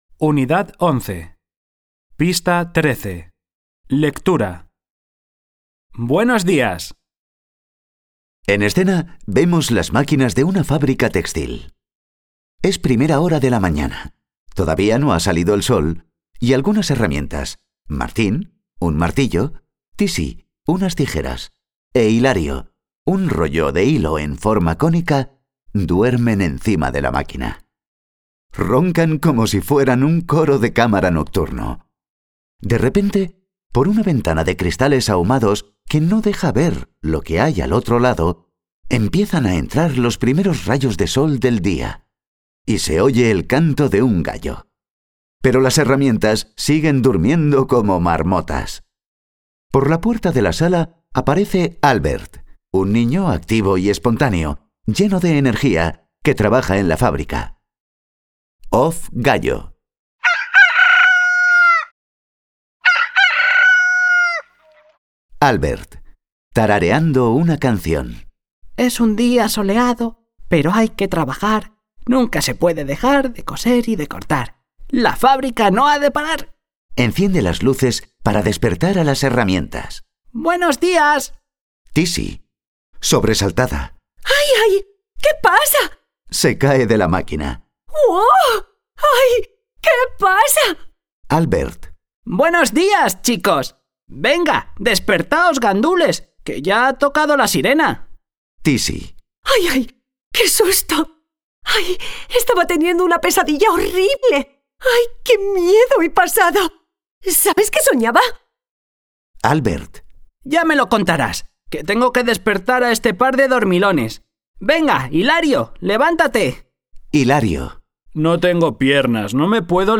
4º_Lengua_Audio_Lectura